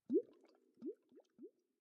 Minecraft Version Minecraft Version snapshot Latest Release | Latest Snapshot snapshot / assets / minecraft / sounds / ambient / underwater / additions / bubbles1.ogg Compare With Compare With Latest Release | Latest Snapshot
bubbles1.ogg